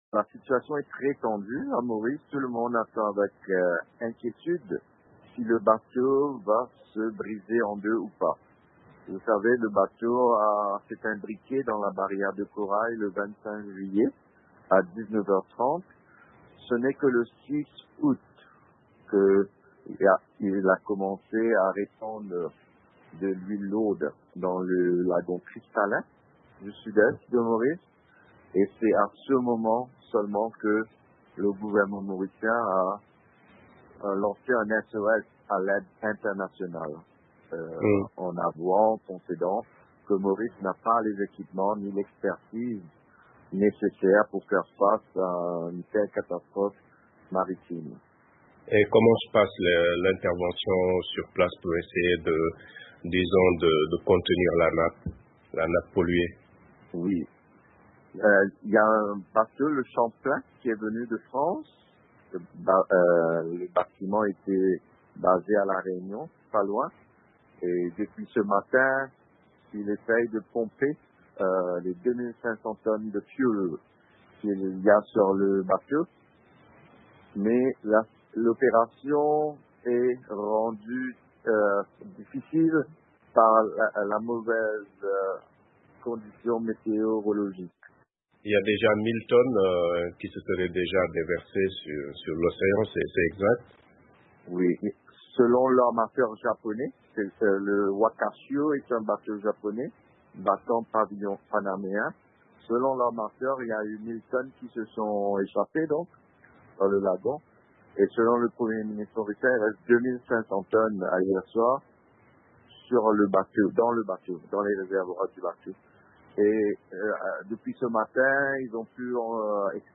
Naufrage du Wakashio: entretien